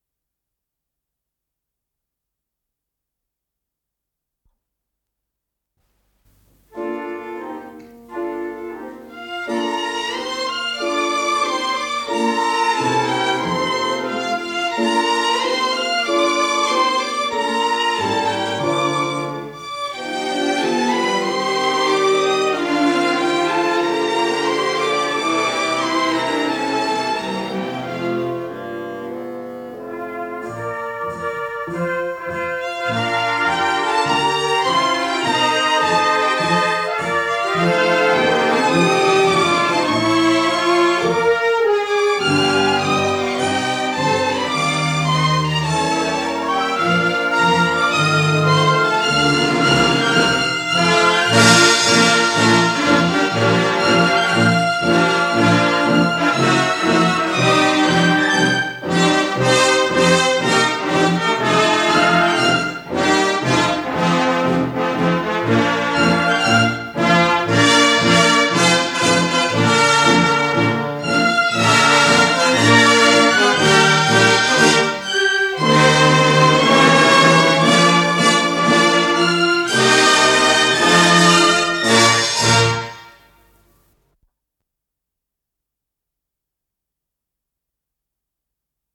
с профессиональной магнитной ленты
ПодзаголовокЗаставка №3